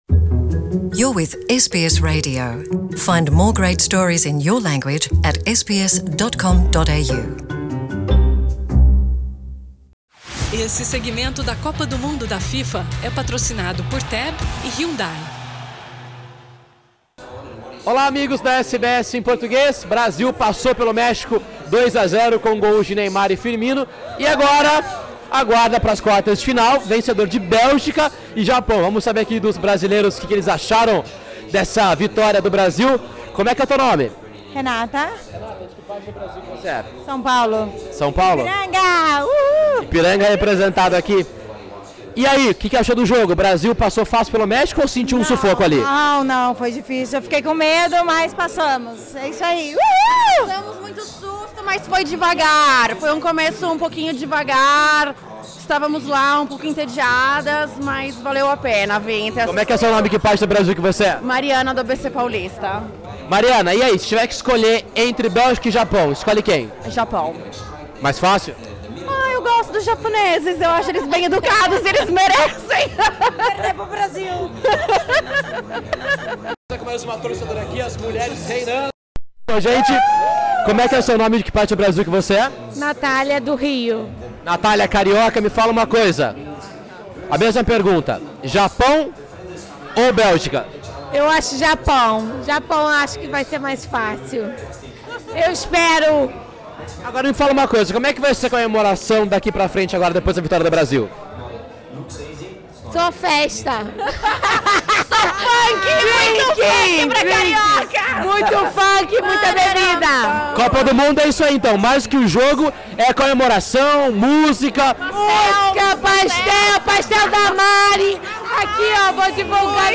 Em Sydney, mais de 150 brasileiros acompanharam a partida com muita comida, bebida e samba no pé. Os brasilerios se reuniram no bairro de Mascot e apesar do frio - 11 graus - a torcida foi bastante animada até o final.